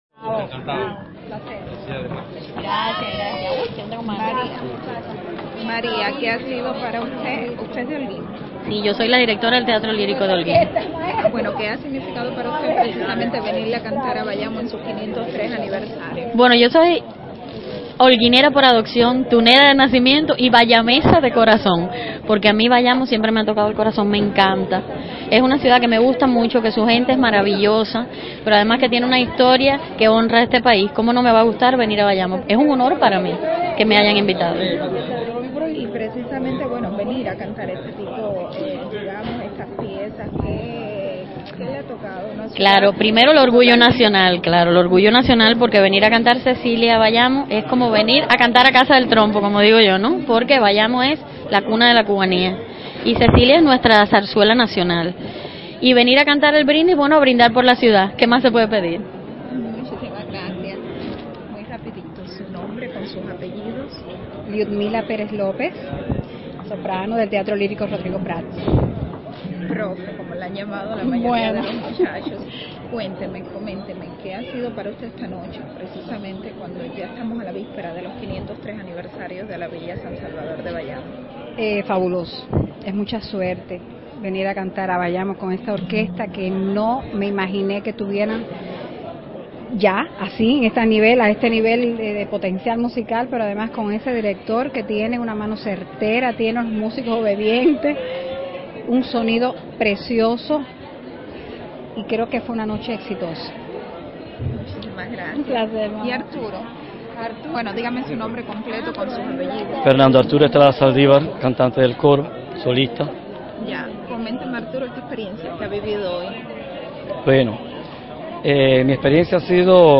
El Coro profesional de Bayamo y la Orquesta sinfónica juvenil de Granma unieron sus voces en un concierto único, nombrado Noche azul, para cantarle a la segunda villa fundada en Cuba, hace hoy 503 años.
en el Teatro Bayamo
sopranos
barítono
Temas del pentagrama nacional e internacional como La Comparsa, Noche Azul, Cecilia Váldez, Brindis de la ópera La Traviata, y Largo al Factótum de la opera El Barbero de Sevilla roban aplausos y expresiones de cortesía a las cerca de 300 personas asistentes.